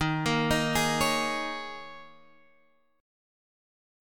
Eb7sus4 Chord